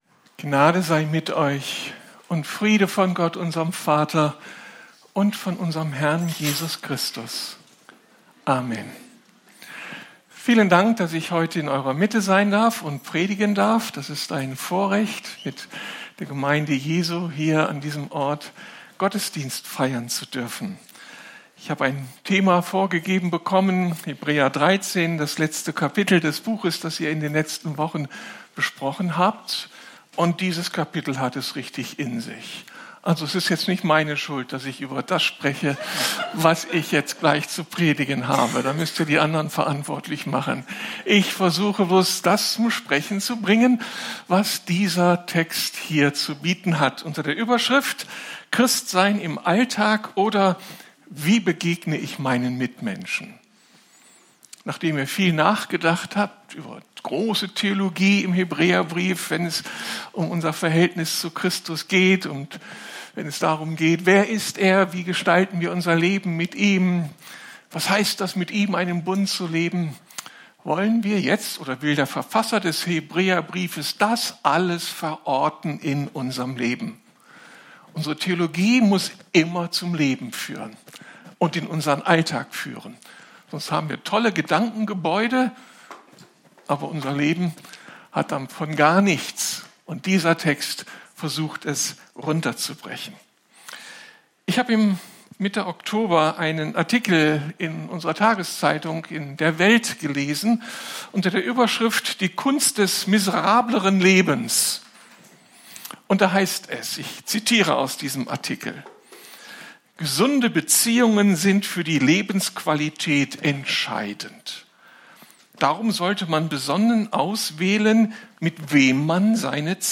Download der Audiodatei Leider fehlen aufgrund technischer Störungen bei der Aufnahme die letzten paar Minuten der Predigt.
Kategorie Predigten